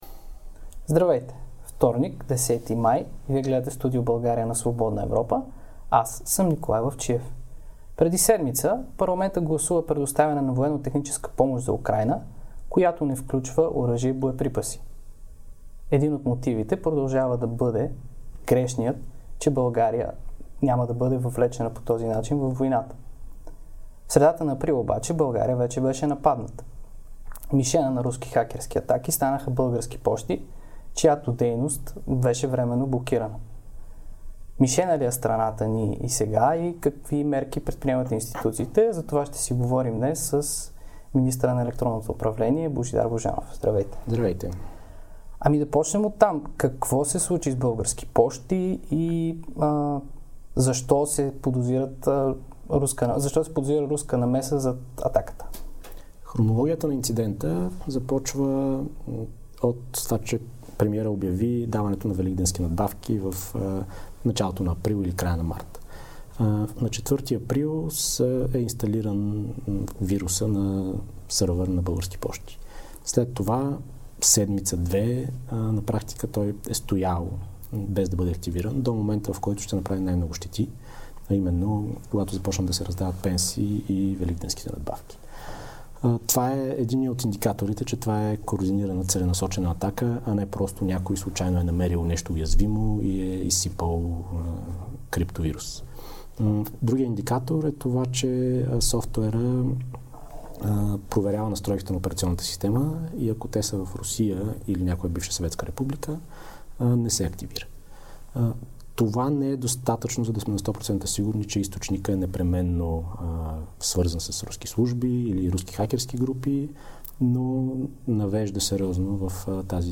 Това каза за атаката срещу Български пощи министърът на електронното управление Божидар Божанов в Студио България на Свободна Европа.